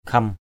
khem.mp3